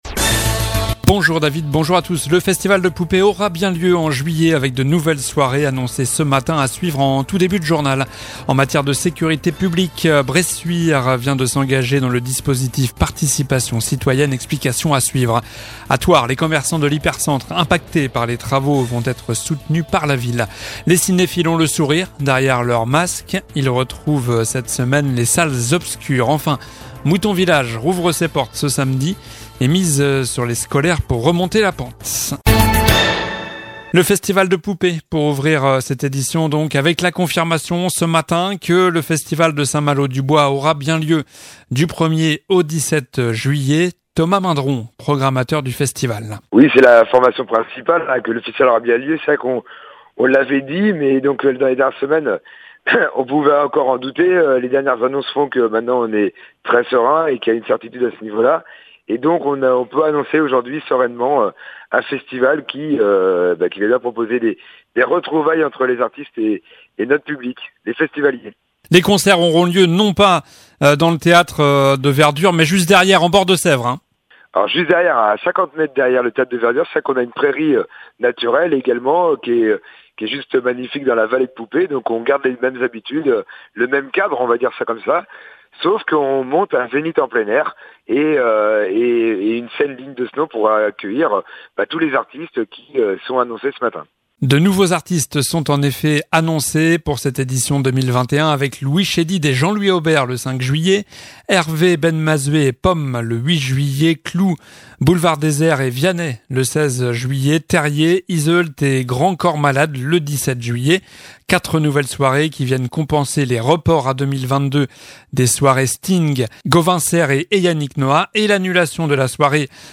Journal du jeudi 20 mai (midi)